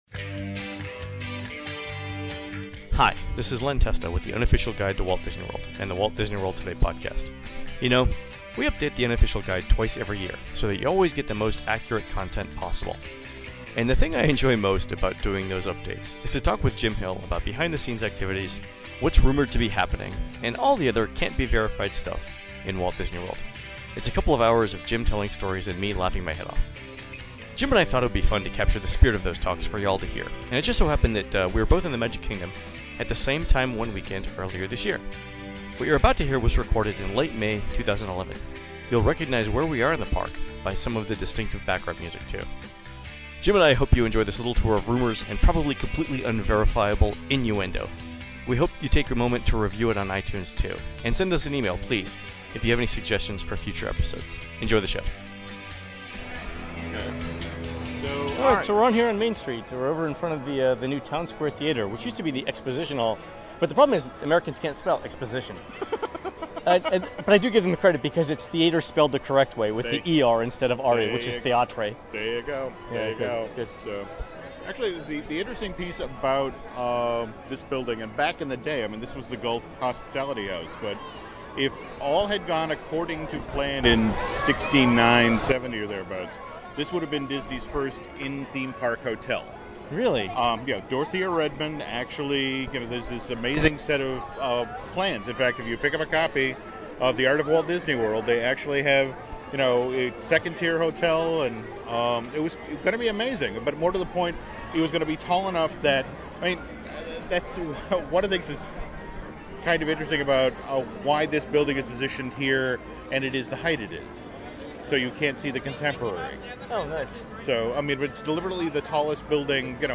Another episode of the Unofficial Guide Disney Dish podcast is available. On this show we walk the Magic Kingdom’s Main Street USA